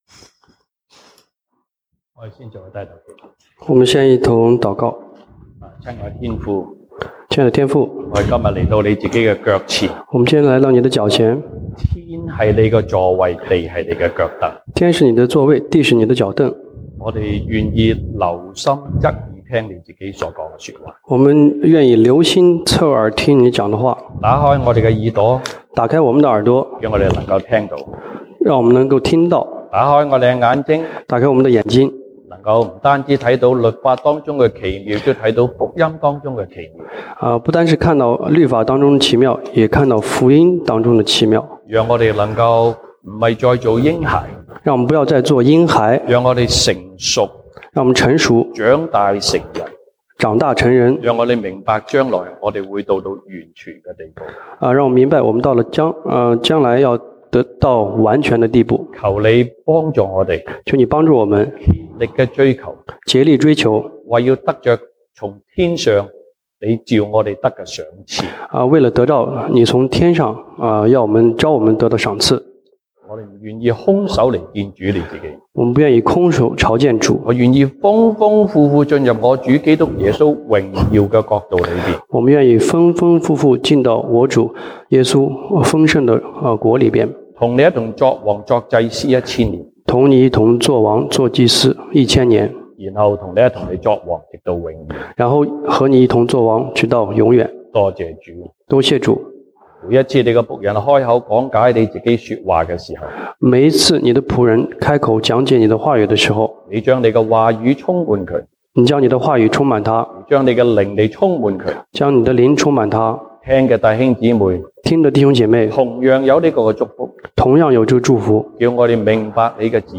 西堂證道(粵語/國語) Sunday Service Chinese: 甚麼是最妙的道?
1 Corinthians Passage: 歌林多前書 1 Corinthians 13:1-13 Service Type: 西堂證道(粵語/國語) Sunday Service Chinese Topics